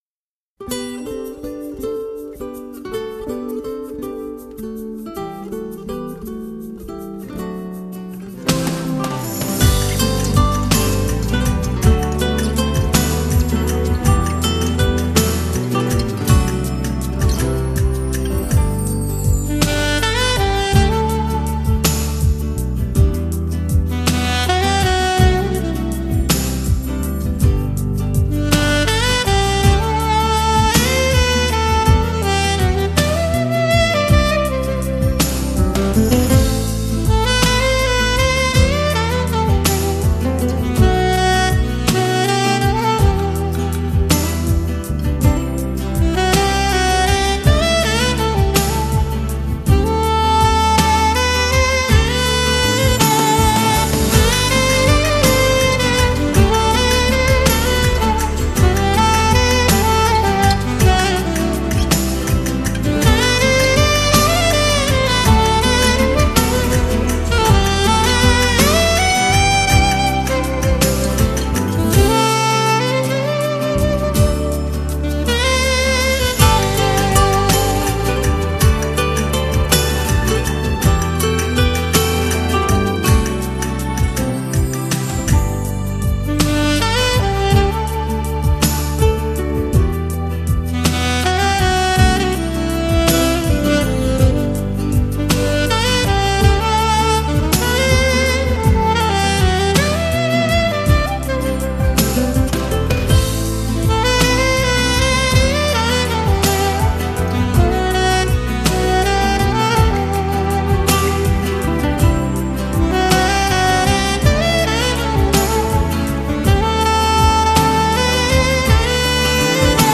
带有拉丁风情的轻松摇摆小品